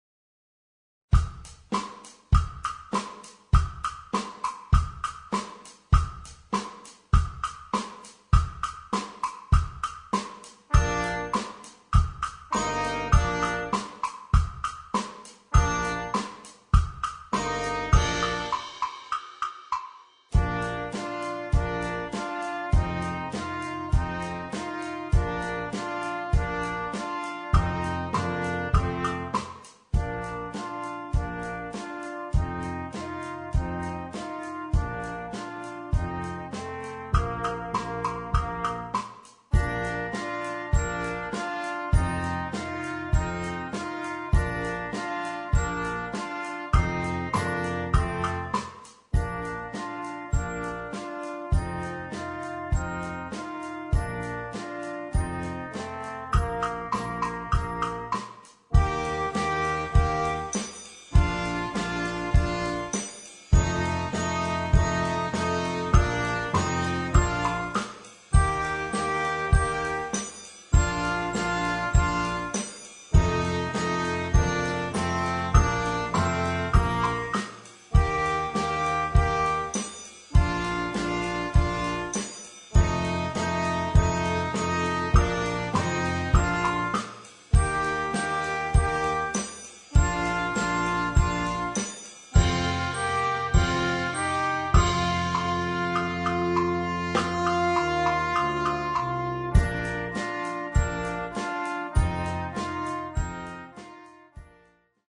Gattung: für flexibles Ensemble
Besetzung: Blasorchester